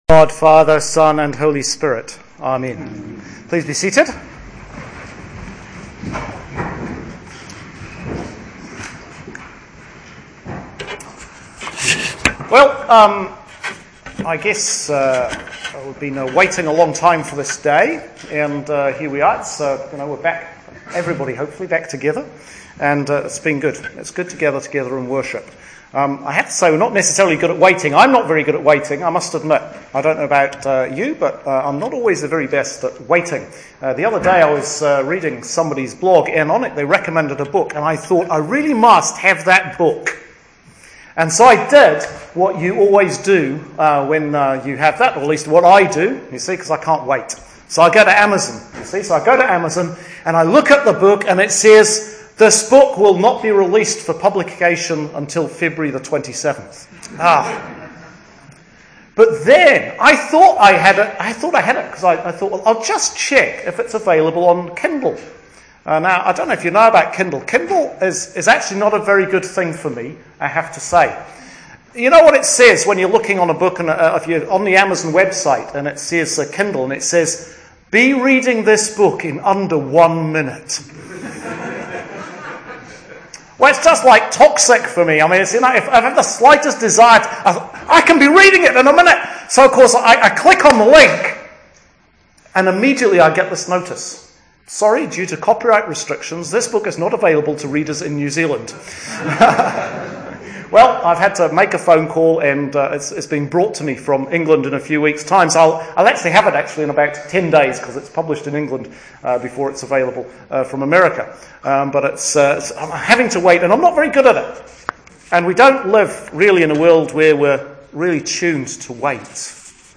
RECORDING OF SERMON – CANDLEMAS